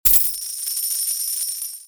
STD_CoinCounter.mp3